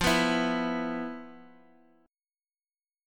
E5/F chord
E-5th-F-x,8,9,9,x,x.m4a